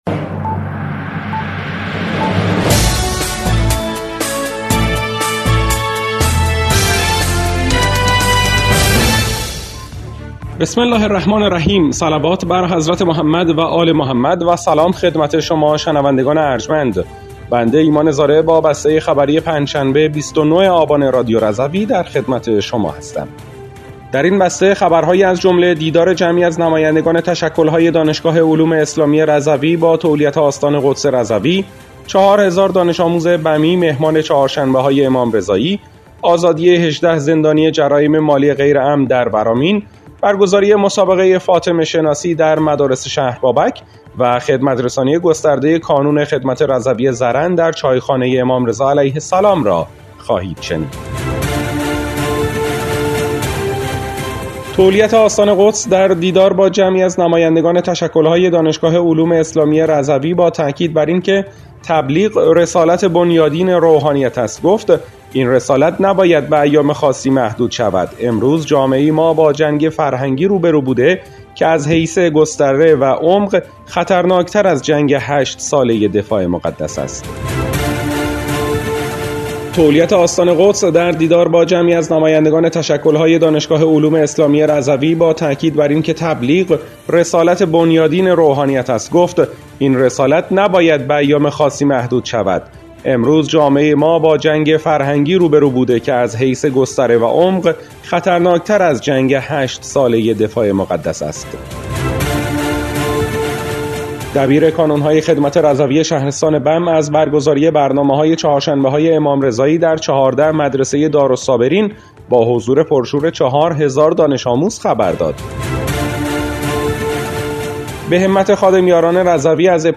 بسته خبری ۲۹ آبان ۱۴۰۴ رادیو رضوی؛